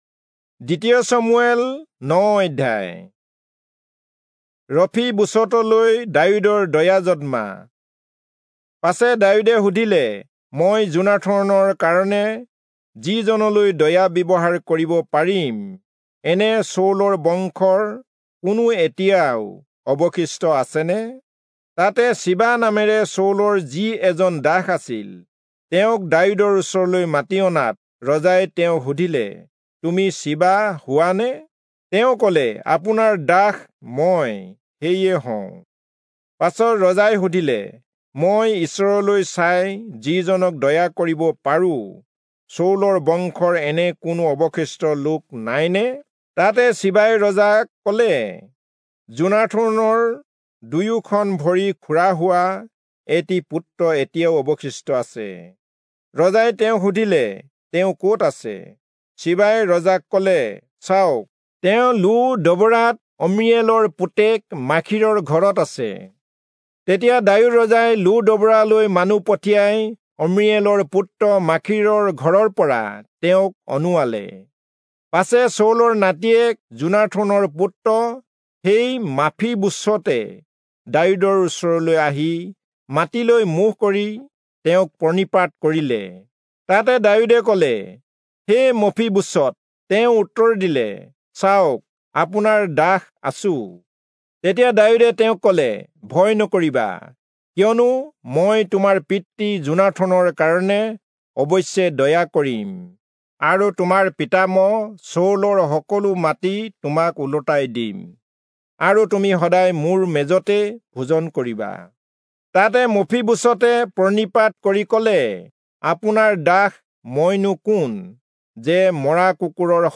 Assamese Audio Bible - 2-Samuel 18 in Litv bible version